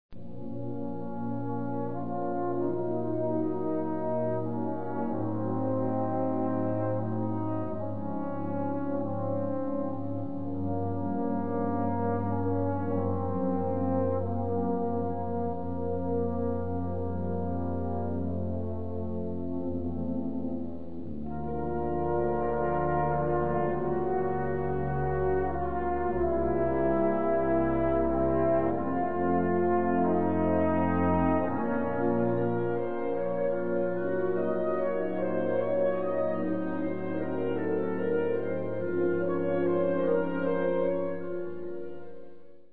Category Concert/wind/brass band
Subcategory Suite
Instrumentation Ha (concert/wind band)
Additional info/contents A suite in four movements
The piece culminates in a pure rhythm.